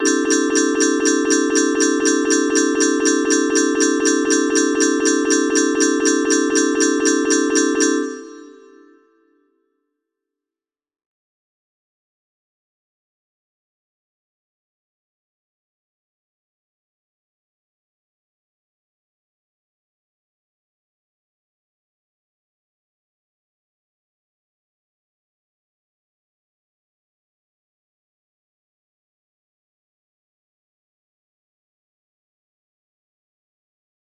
Head smack against a desk music box melody